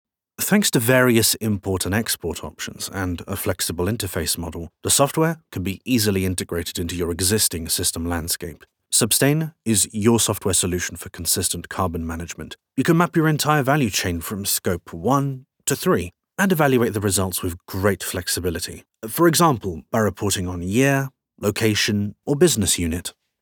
Versatile English voice over, ranging from warm and engaging to theatrical, funny or conversational.
Sprechprobe: eLearning (Muttersprache):